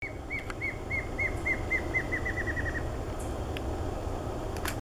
Choquinha-lisa (Dysithamnus mentalis)
Nome em Inglês: Plain Antvireo
Localidade ou área protegida: Parque Nacional Iguazú
Condição: Selvagem
Certeza: Fotografado, Gravado Vocal